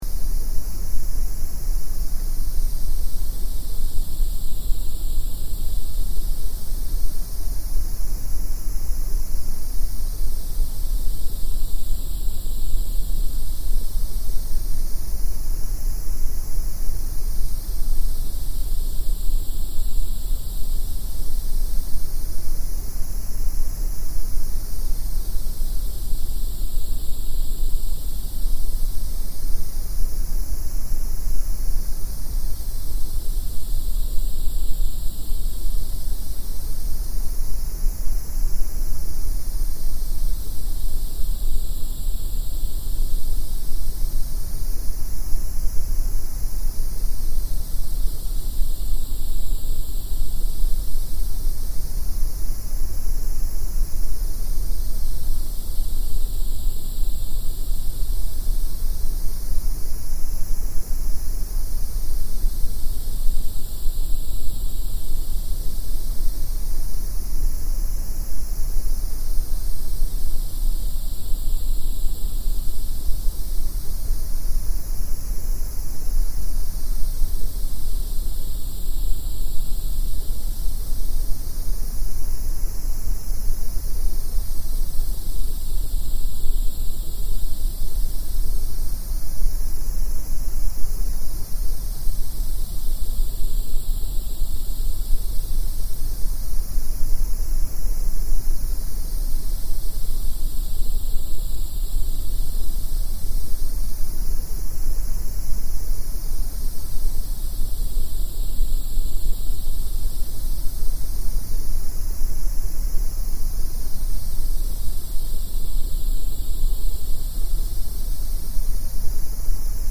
Masking Sound Simulated Surf Grey Moderate Intensity